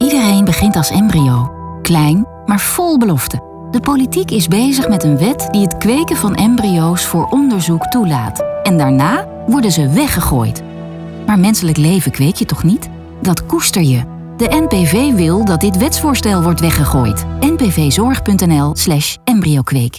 Vanaf aanstaande maandag klinkt op de radio
een commercial over embryokweek. Deze commercial is onderdeel van de campagne 'Menselijk leven kweek je niet, dat koester je’ van NPV- Zorg voor het leven.